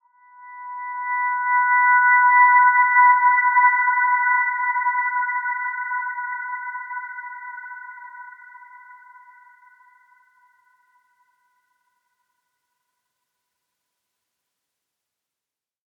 Dreamy-Fifths-B5-f.wav